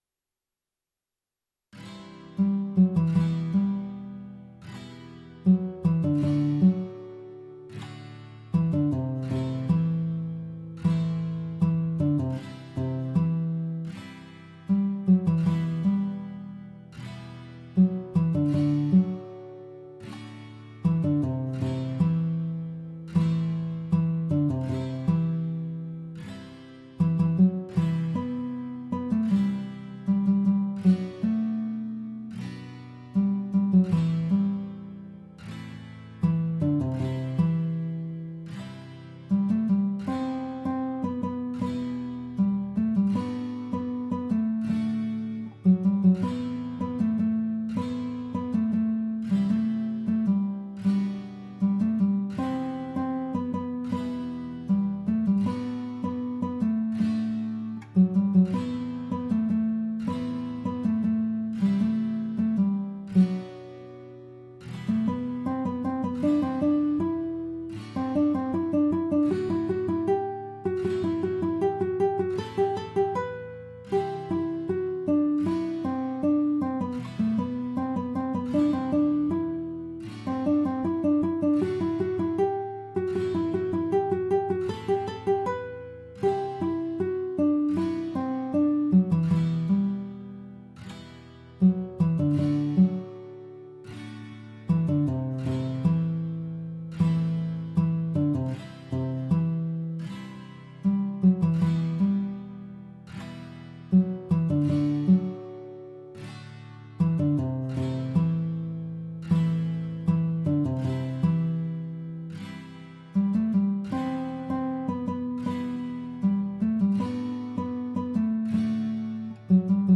ملودی گیتار